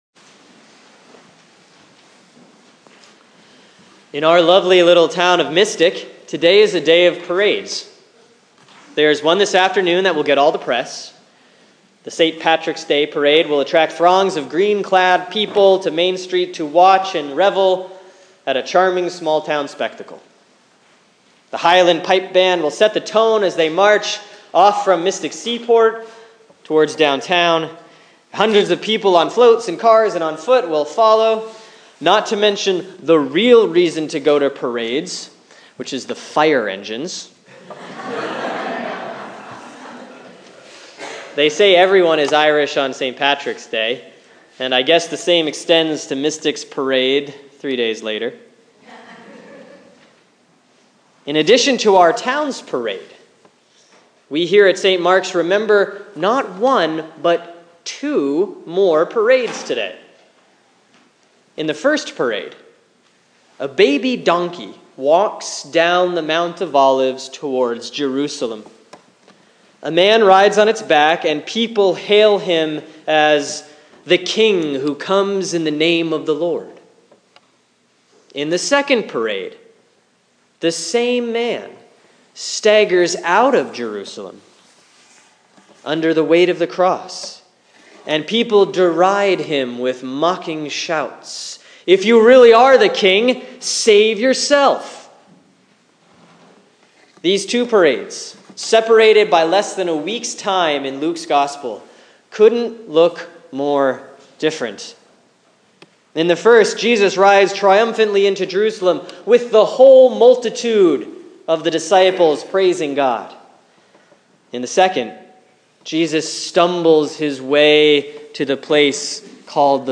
Sermon for Sunday, March 20, 2016 || Palm/Passion Sunday C || Luke 19:28-40; Luke 23